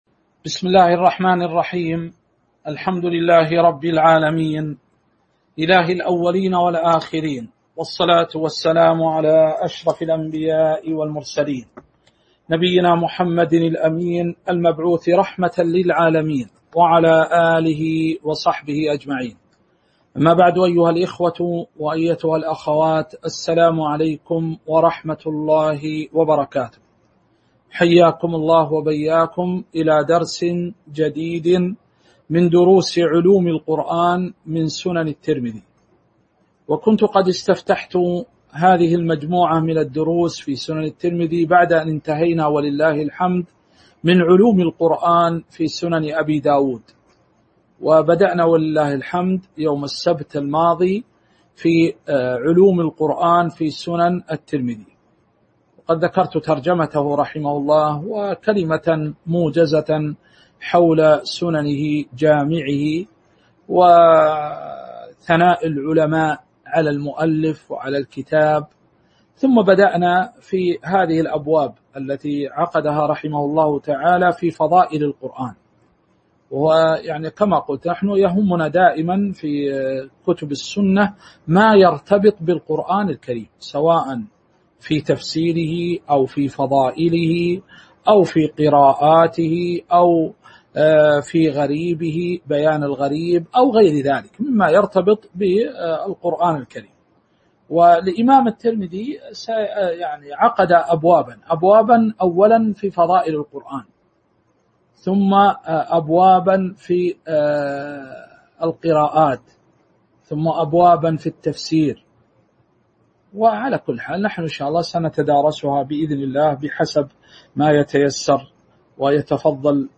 تاريخ النشر ٨ محرم ١٤٤٣ هـ المكان: المسجد النبوي الشيخ